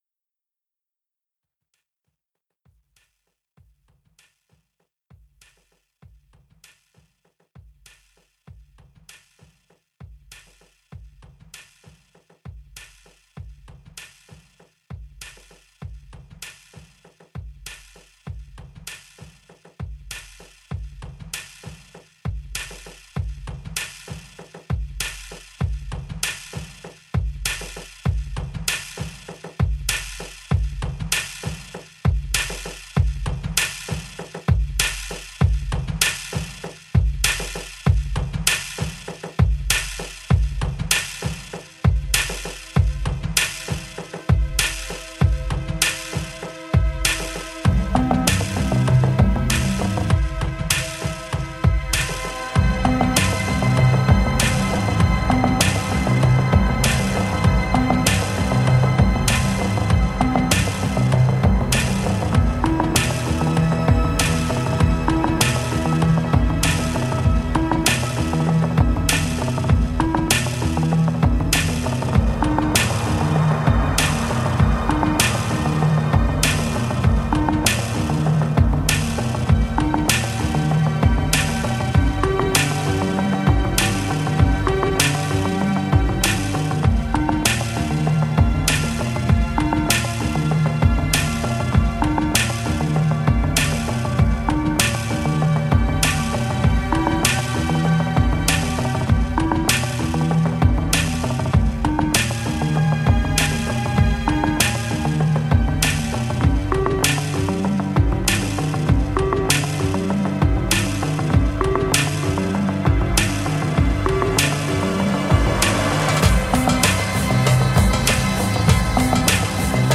envoûtant et métronomique